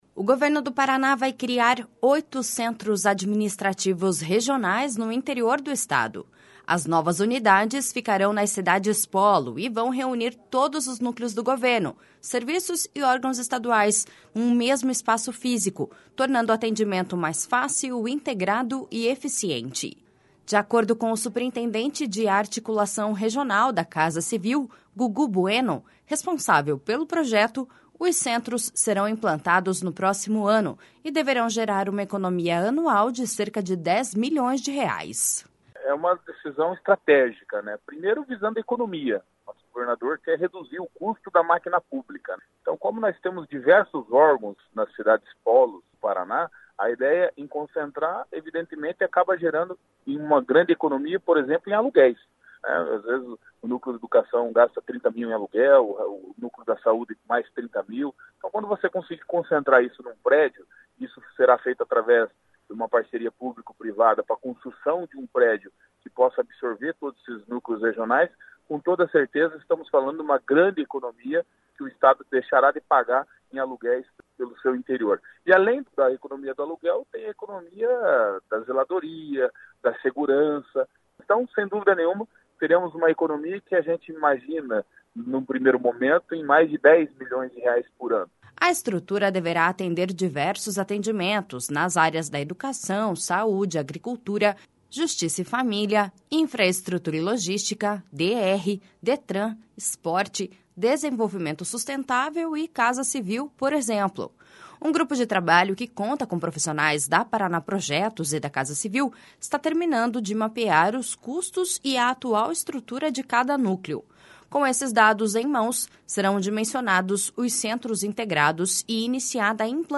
De acordo com o superintendente de Articulação Regional da Casa Civil, Gugu Bueno, responsável pelo projeto, os centros serão implantados no próximo ano e deverão gerar uma economia anual de cerca de 10 milhões de reais.// SONORA GUGU BUENO.//